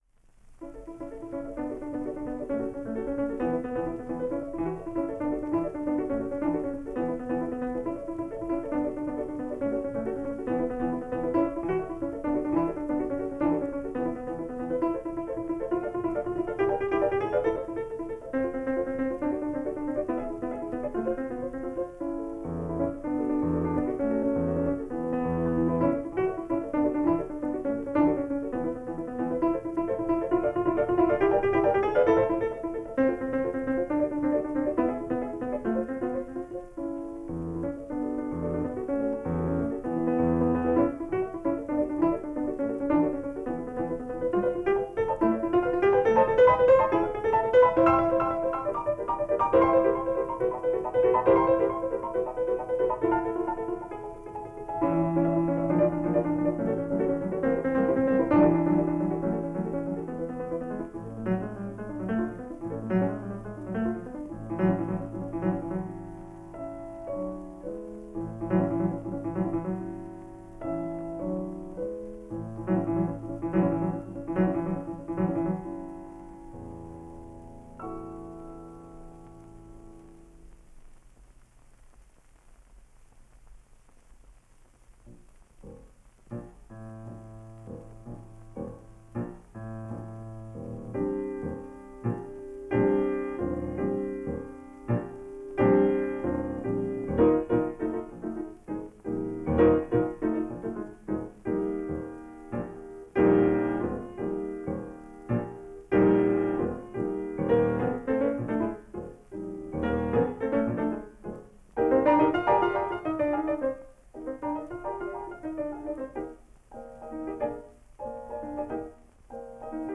pianist
The three record 78 set